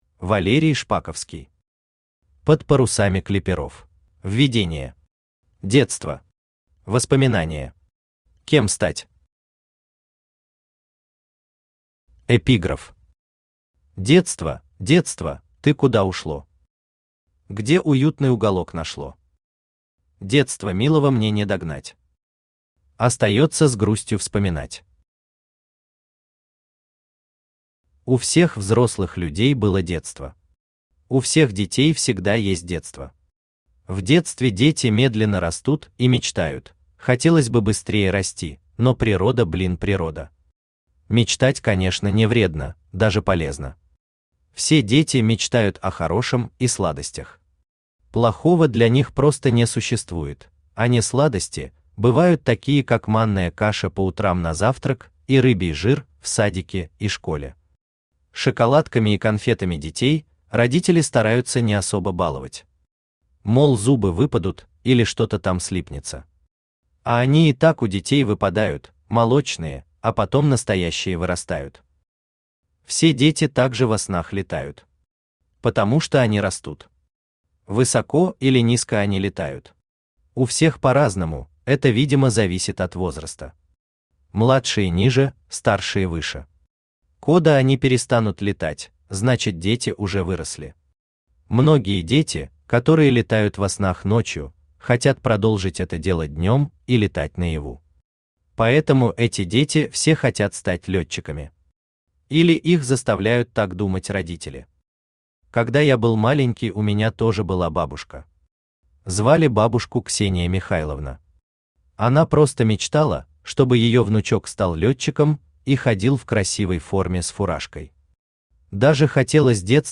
Aудиокнига Под парусами клиперов Автор Валерий Николаевич Шпаковский Читает аудиокнигу Авточтец ЛитРес.